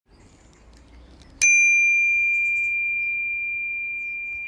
Campanello Knog OI CLASSIC
Oi di Knog è il campanello bici dal design molto elegante e dal suono forte ma piacevole.